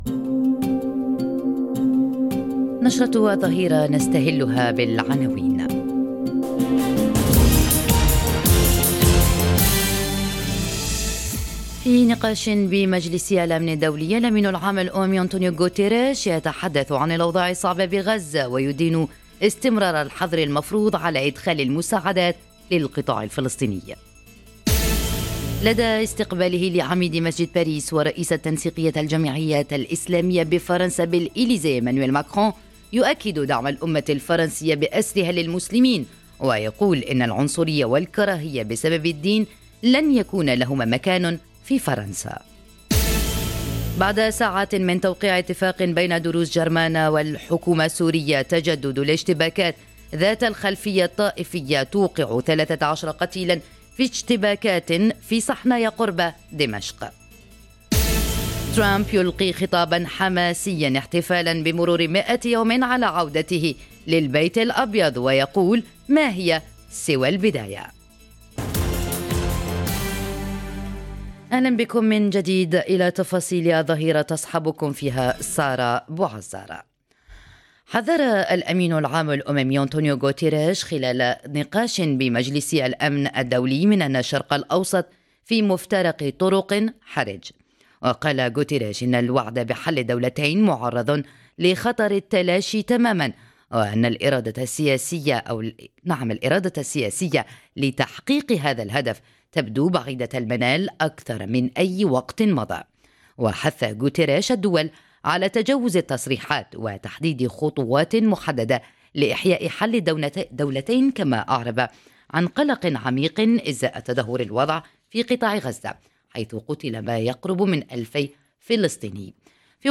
نشرة أخبار الظهيرة: غزة تنزف وتحذير أممي من تلاشي حل الدولتين وسط توتر دولي وانتقادات لإسرائيل وقراراتها - Radio ORIENT، إذاعة الشرق من باريس